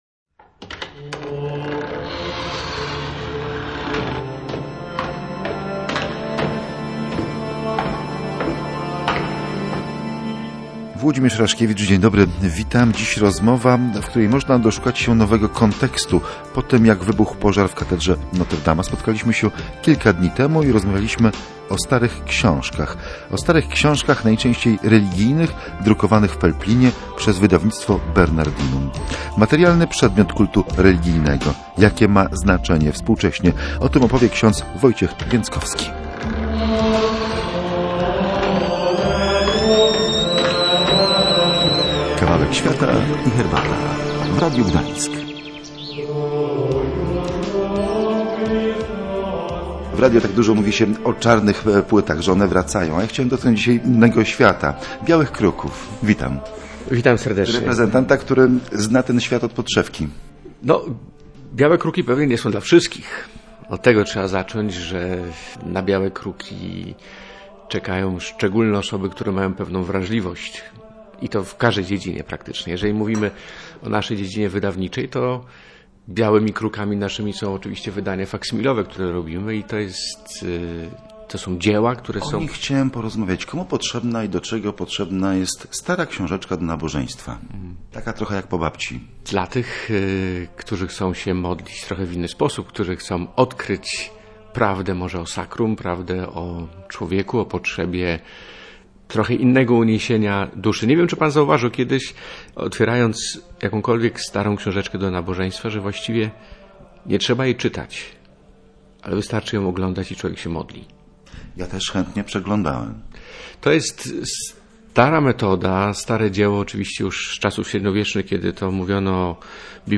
Rozmowa o starych książkach, najczęściej religijnych, drukowanych w Pelplinie przez Wydawnictwo Bernardinum.